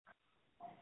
Screams from November 29, 2020
• When you call, we record you making sounds. Hopefully screaming.